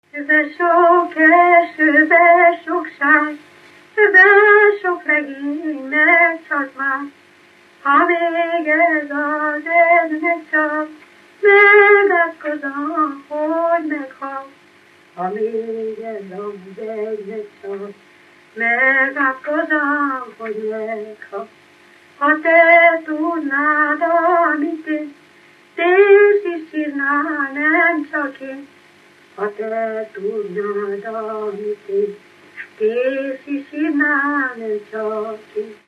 Erdély - Kolozs vm. - Válaszút
Műfaj: Lassú cigánytánc
Stílus: 3. Pszalmodizáló stílusú dallamok